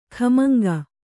♪ khamaŋga